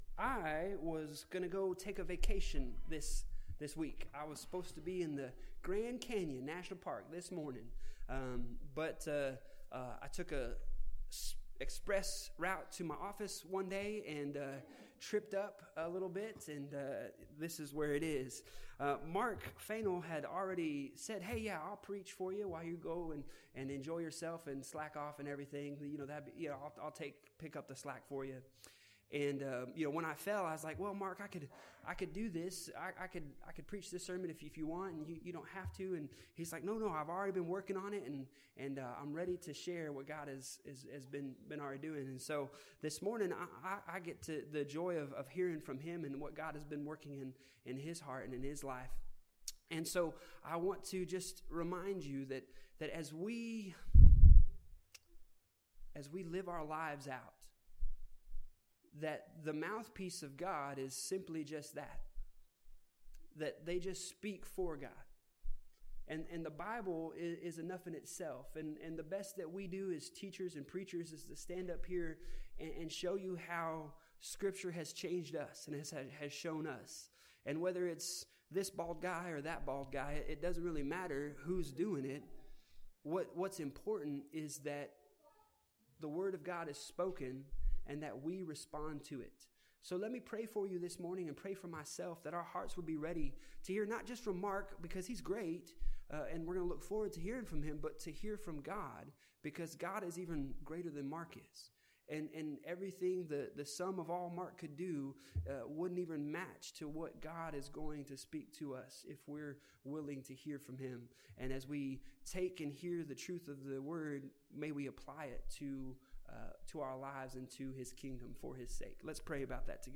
Bible Text: Matthew 21:18-22 | Preacher